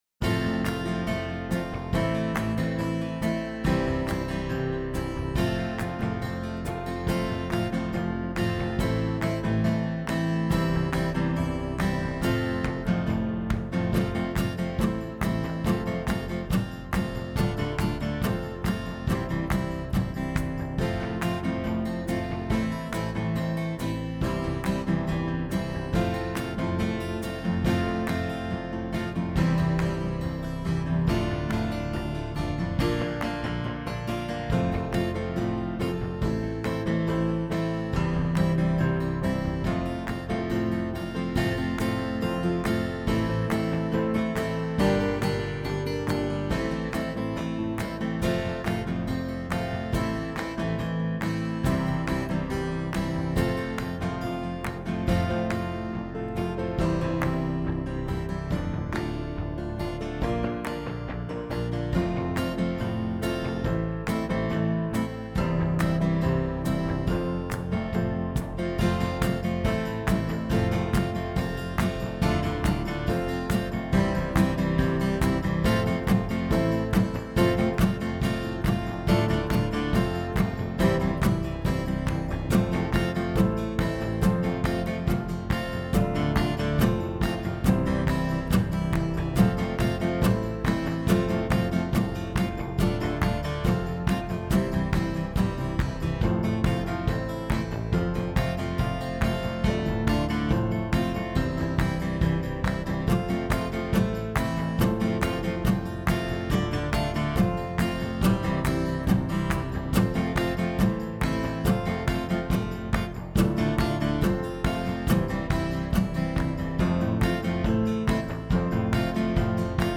Музыка написана системой искусственного интеллекта Amper AI.